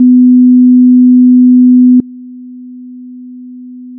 [mp3]       50Hzの音データ/基準音と低減音 [自動車の低音マフラーなど] 80Hzの音データ/基準音と低減音 [いびきや大型犬の鳴き声など] 100Hzの音データ/基準音と低減音 [会話音[男性の声]など] 125Hzの音データ/基準音と低減音 [自動車の低音マフラーなど]